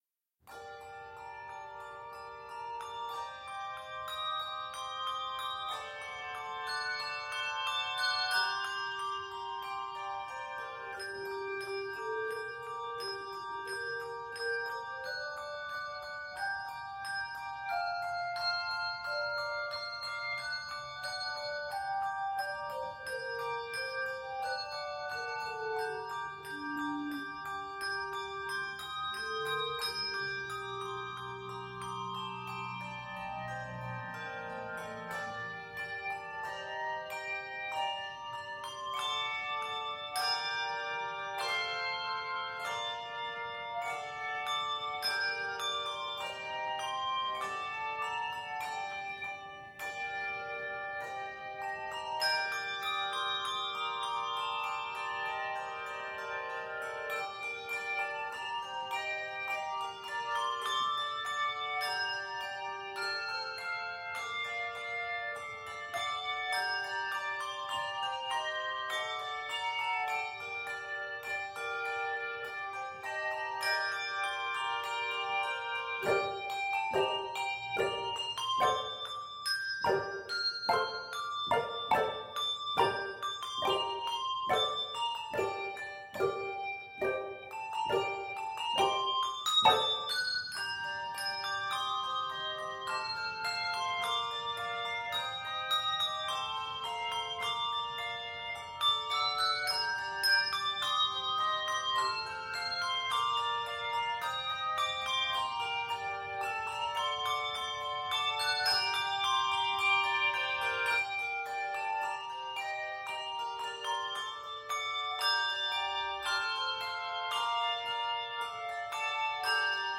two octave choir
it is scored in g minor.